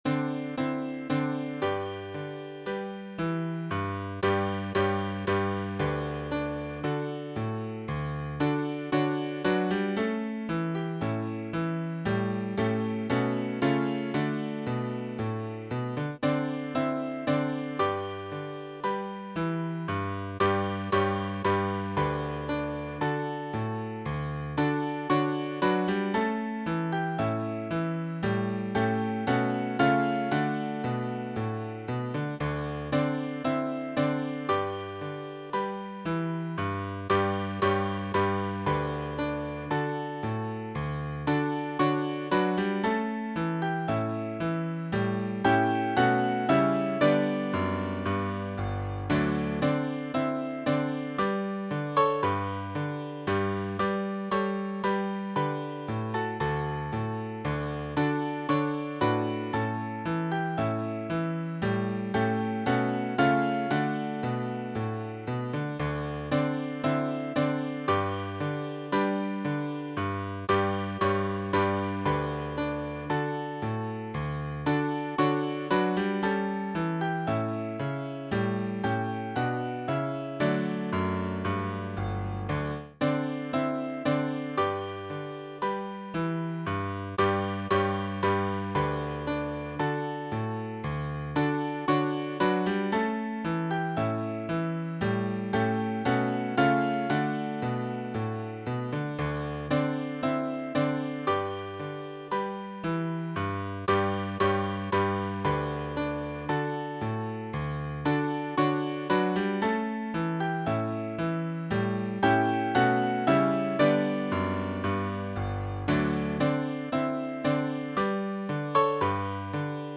Small Band
Vocals and Band   750.3kb 2.3mb